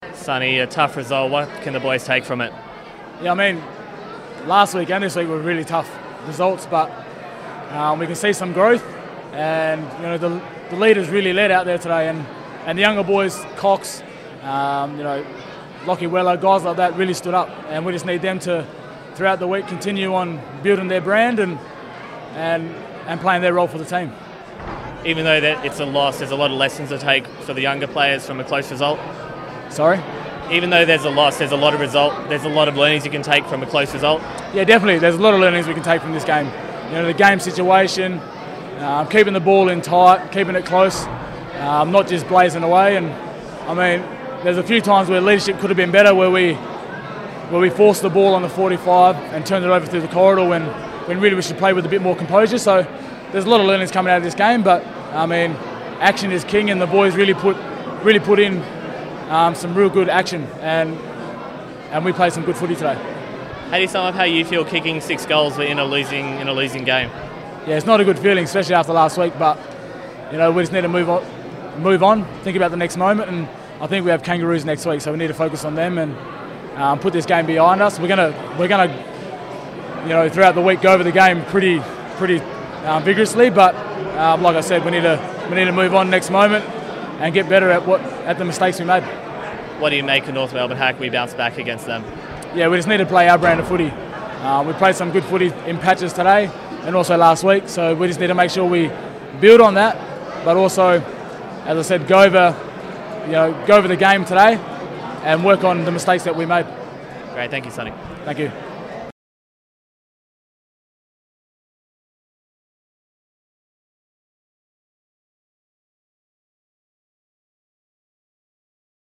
Michael Walters chats to Docker TV after Freo's clash against the Saints.